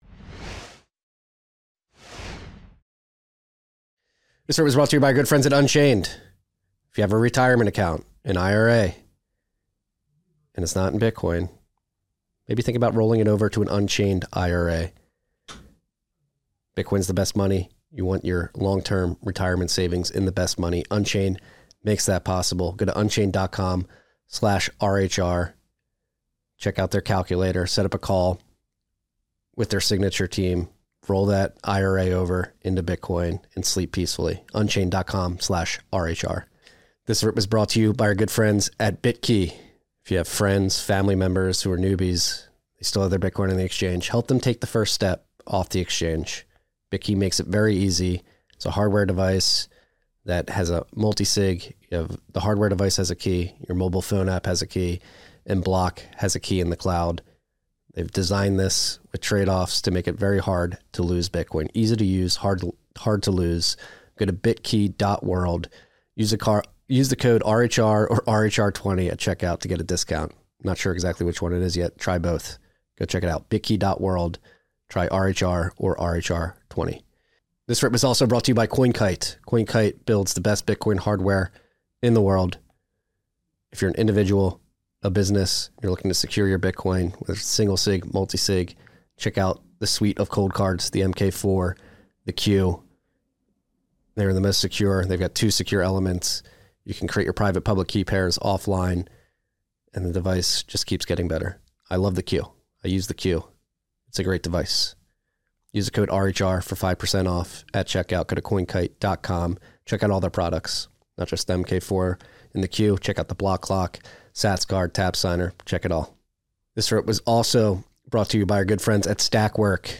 RABBIT HOLE RECAP #342: LIVE FROM BITCOIN PARK